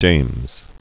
(dāmz)